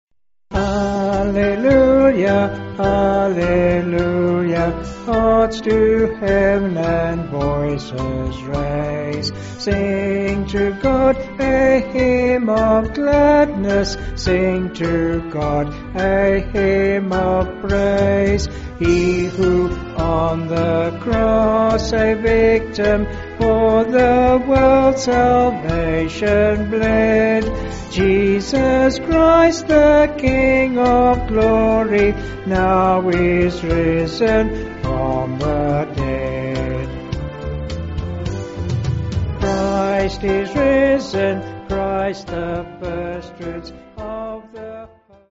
Vocals and Band
265.7kb Sung Lyrics 2.7mb